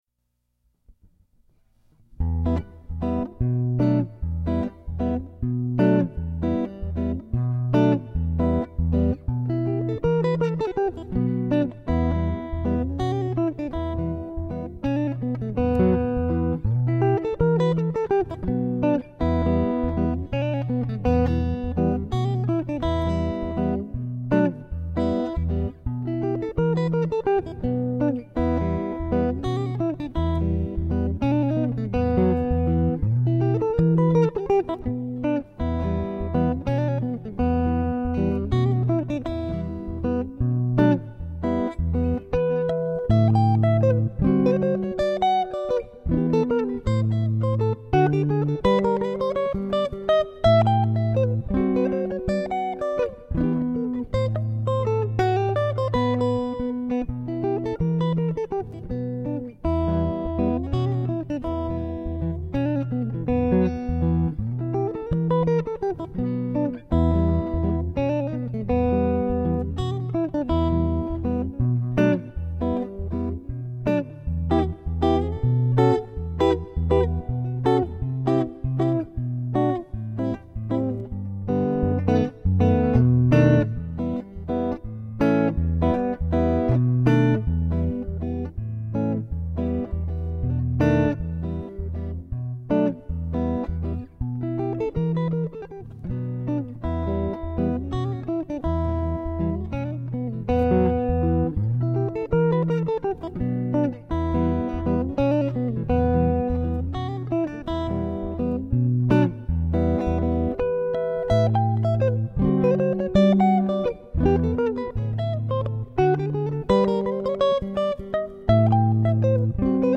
Fingerstyle Guitar: